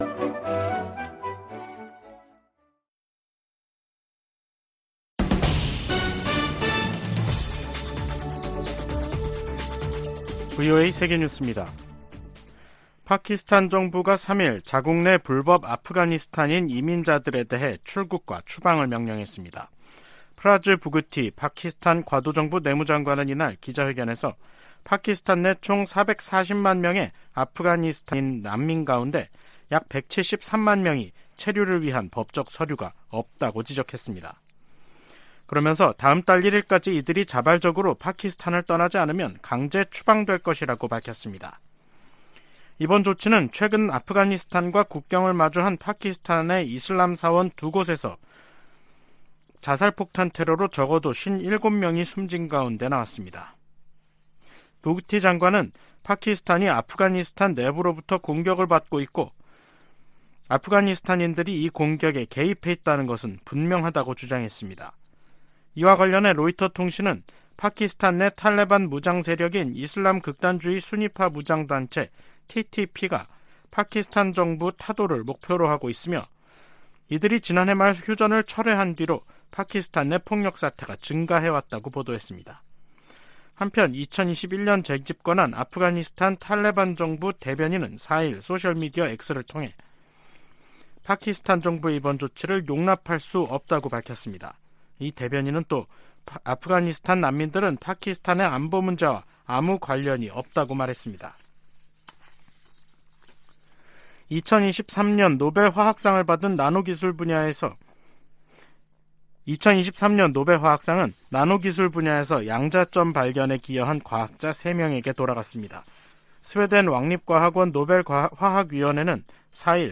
VOA 한국어 간판 뉴스 프로그램 '뉴스 투데이', 2023년 10월 4일 3부 방송입니다. 북한 국방성은 미국의 '2023 대량살상무기(WMD) 대응 전략'을 "또 하나의 엄중한 군사정치적 도발"이라고 규정했습니다. 미 국무부는 핵 보유가 주권 행사라는 최선희 북한 외무상의 주장에, 북한은 절대 핵보유국으로 인정받지 못할 것이라고 강조했습니다. 미 국방부는 북한과 러시아 간 추가 무기 거래가 이뤄져도 놀랍지 않다는 입장을 밝혔습니다.